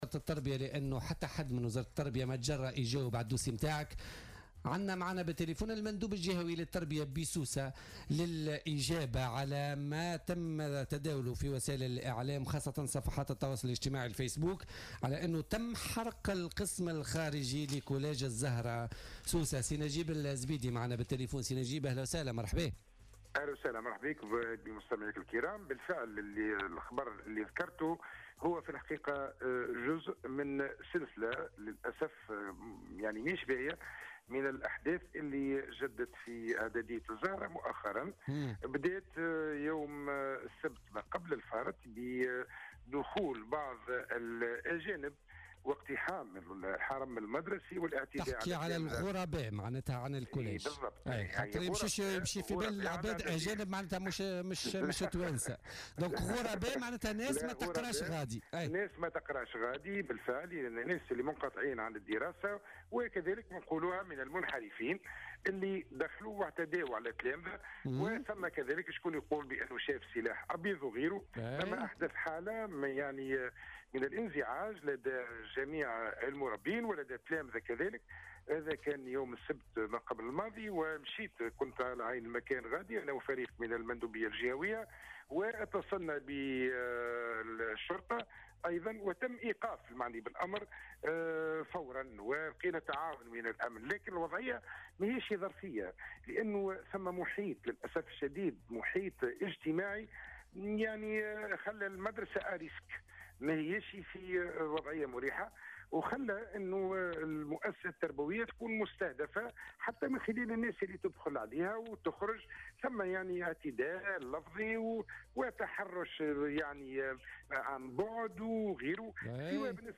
أكد المندوب الجهوي للتربية بسوسة نجيب الزيدي في مداخلة له في بولتيكا اليوم الثلاثاء أنه تم حرق القسم الخارجي للمدرسة الاعدادية الزهراء بسوسة .